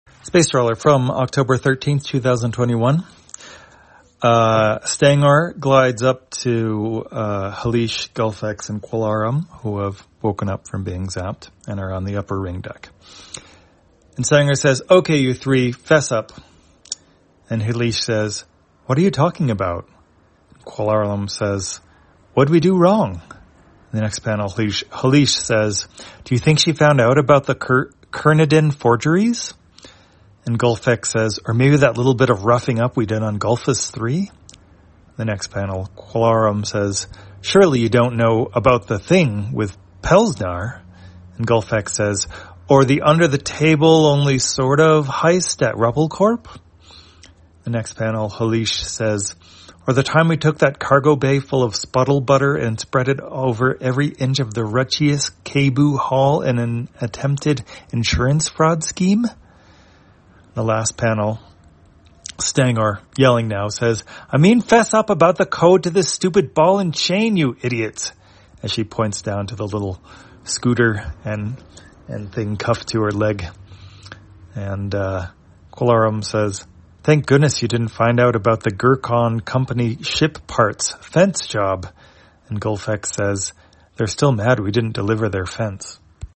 Spacetrawler, audio version For the blind or visually impaired, October 13, 2021.